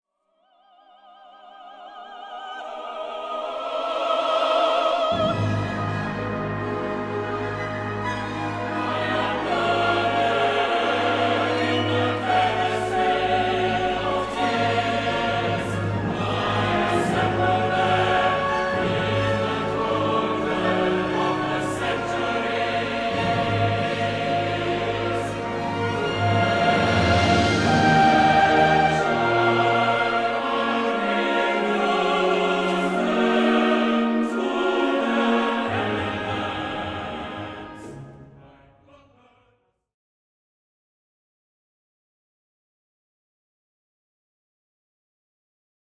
Bournemouth Symphony Chorus and Youth Chorus are joined by the esteemed Salomon Orchestra to perform Richard Blackford’s new work, The Black Lake.
Inspired by the great Welsh novel One Moonlit Night, Richard Blackford’s soaring yet poignant work The Black Lake, tells the story of a boy’s passage to adulthood whilst growing up in a remote village in North Wales.
Baritone
Soprano